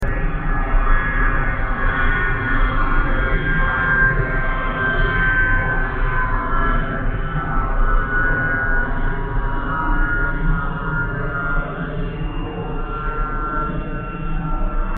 Additionally, exemplary real-time auralizations of aircraft noise based on the presented method for interpolating simulation results are provided (linear